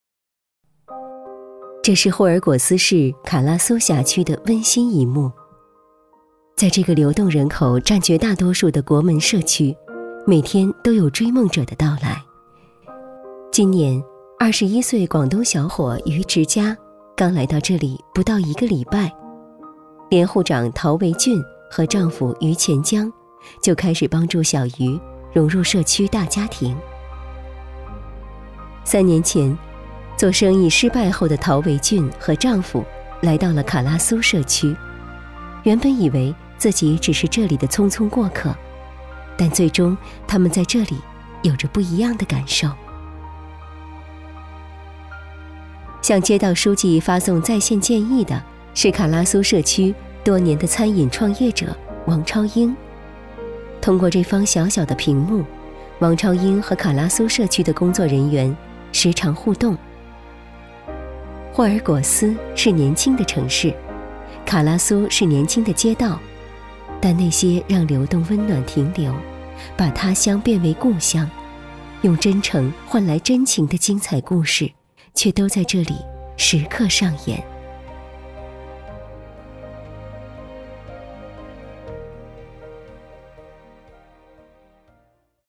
女国语330
【纪录片】卡拉苏社区